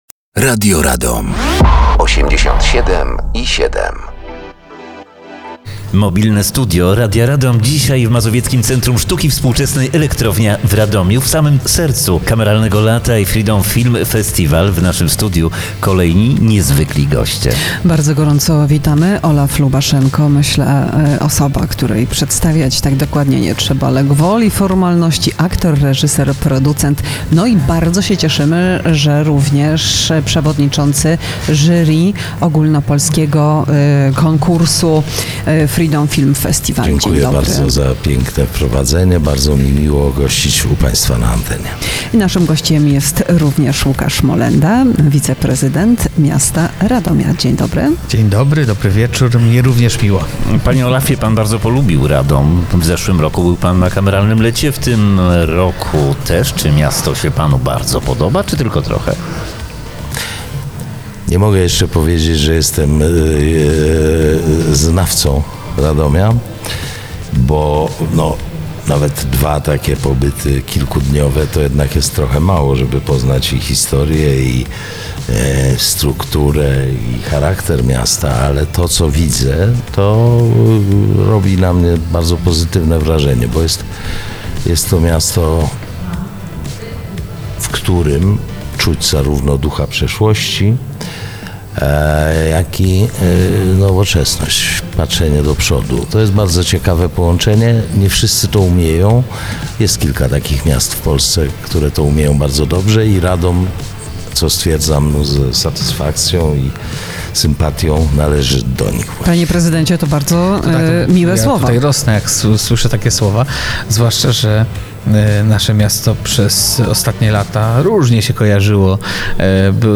Gościmi Mobilnego Studia Radia Radom byli aktor Olaf Lubaszenko i wiceprezydent Radomia Łukasz Molenda.